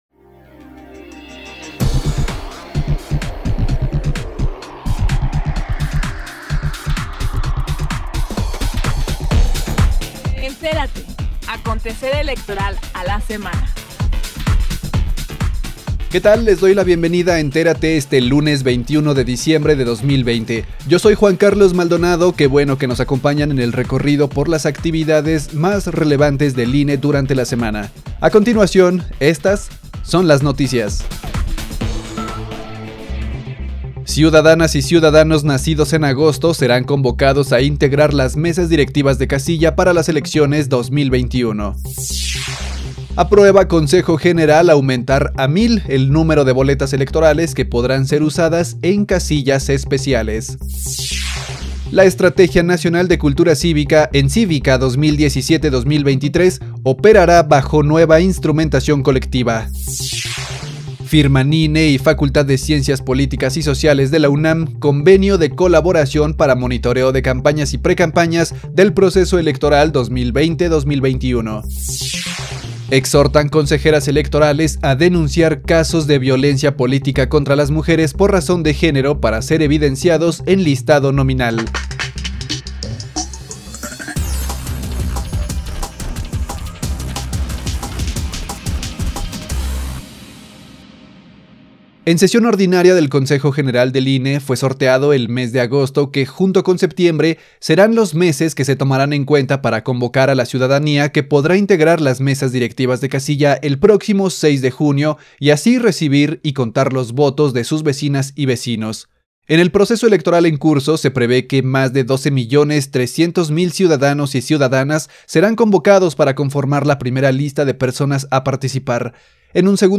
NOTICIARIO-21-DE-DICIEMBRE-2020